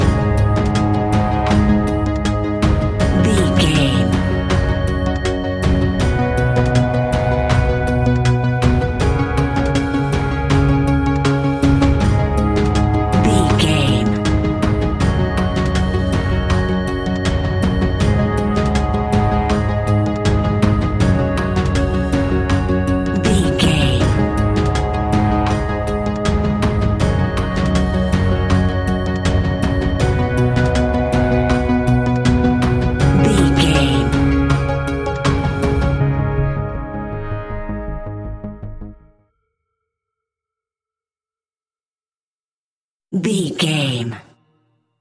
Aeolian/Minor
A♭
ominous
dark
eerie
synthesizer
drum machine
Horror Pads